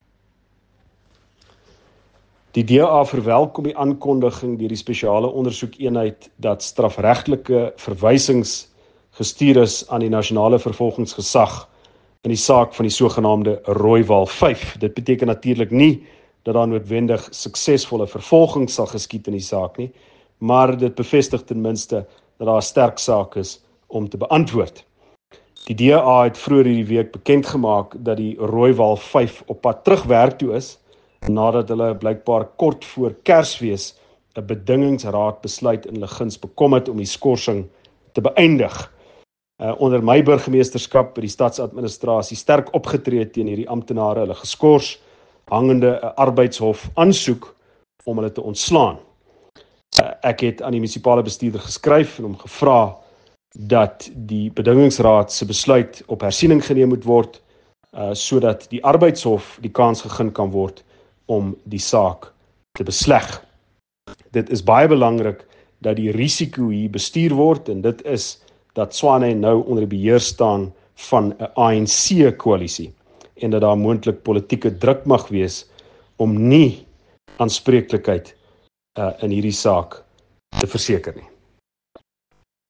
Note to Editors: Please find English and Afrikaans soundbites by Ald Cilliers Brink here, and